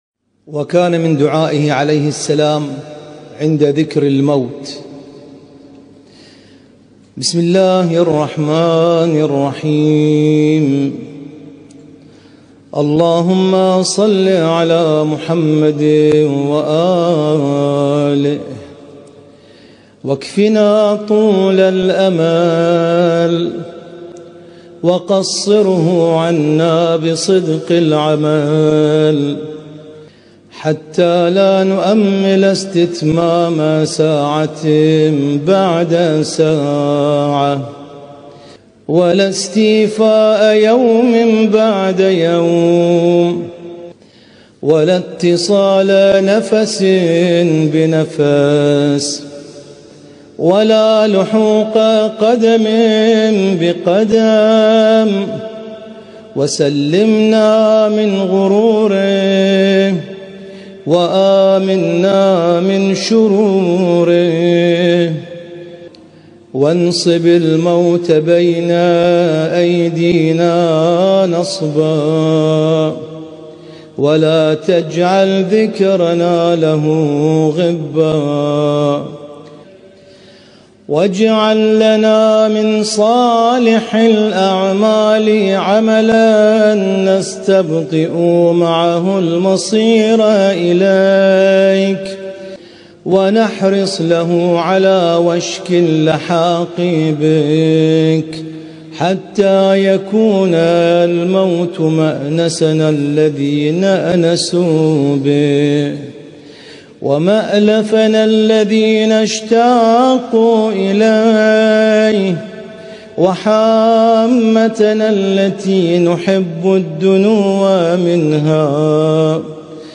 القارئ: